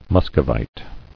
[Mus·co·vite]